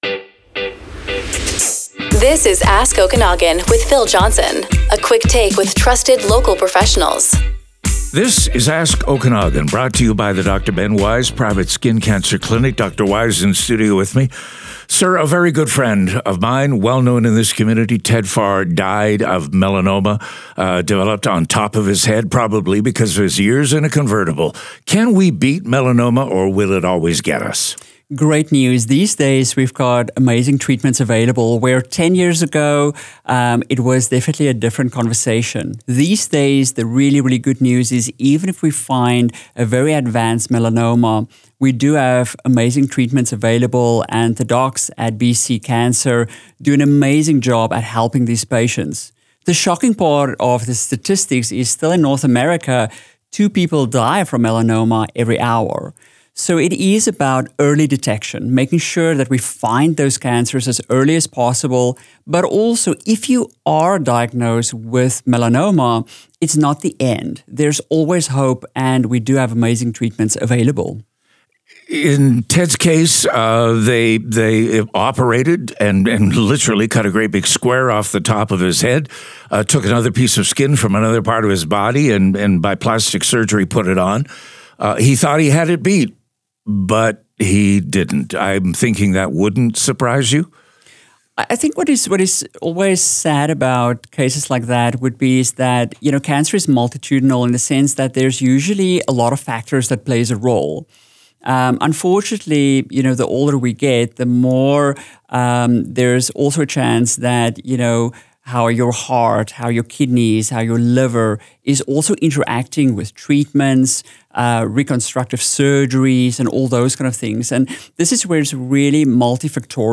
This media recording reflects a public interview and is shared for educational purposes only.